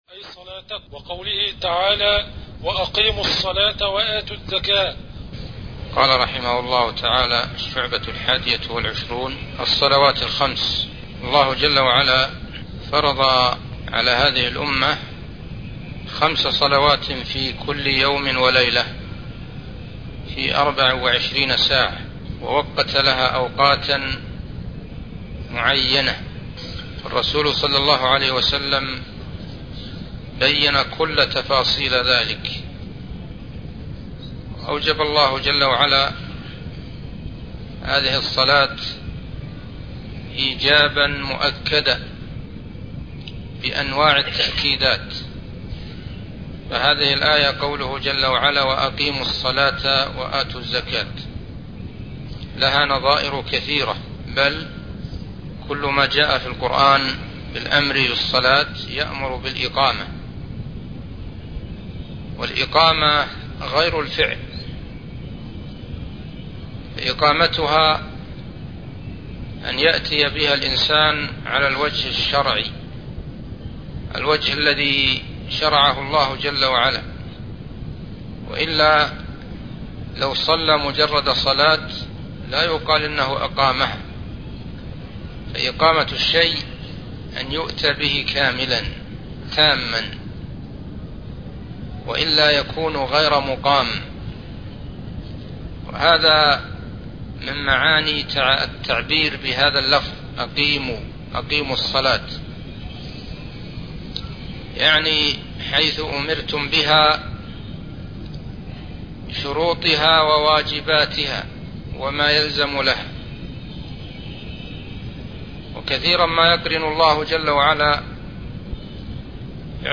الدرس (7) شرح مختصر شعب الإيمان